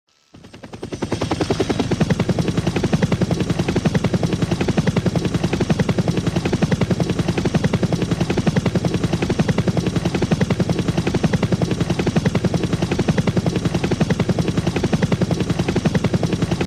Звуки пропеллера
Шум лопастей вертолета